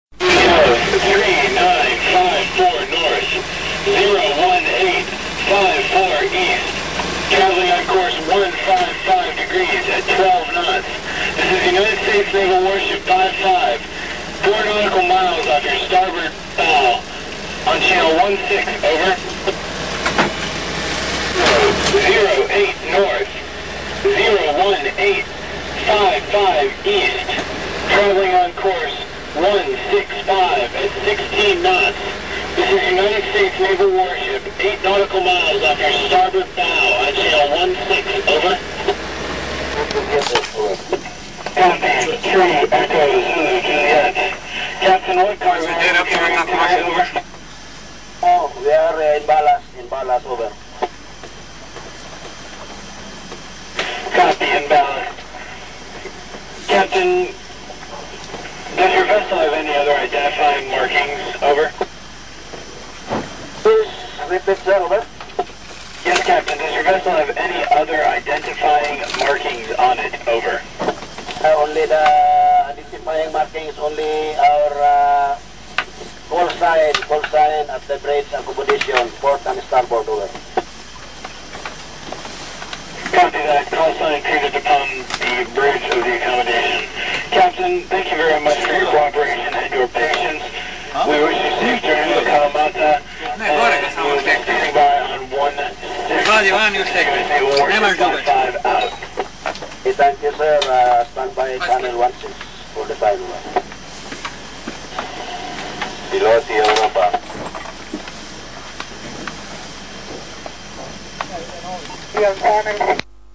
Predvečer imali predstavu na VHF u režiji "US warshipa". Brod proziva sve koji su mu u blizini, da se identificiraju, pa smo tako čekali na naš red, ali nismo bili zanimljivi valjda.